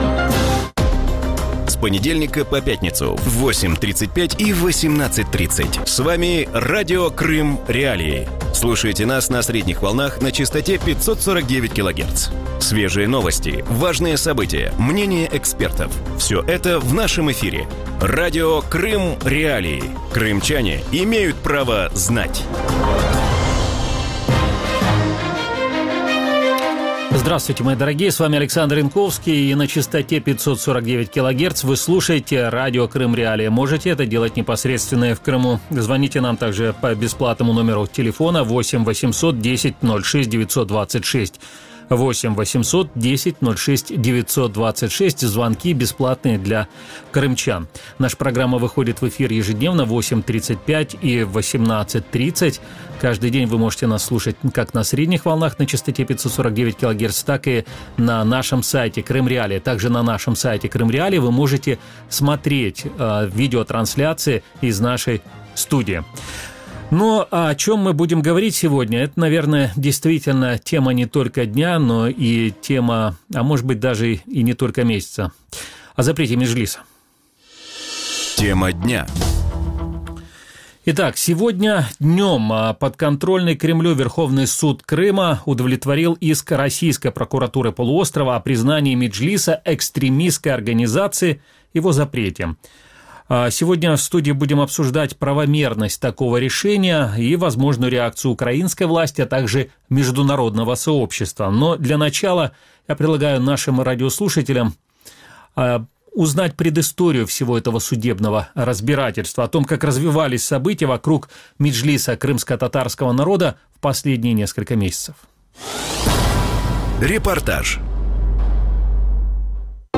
В вечернем эфире Радио Крым.Реалии обсуждают запрет Меджлиса крымскотатарского народа подконтрольным Кремлю Верховным судом Крыма. Что предпримут лидеры крымских татар, какой будет реакция Украины и международного сообщества – эти вопросы обсудят глава правления Центра гражданских свобод Александра Матвийчук, первый заместитель главы Меджлиса Нариман Джелял и постоянный представитель Украины при Совете Европы Дмитрий Кулеба.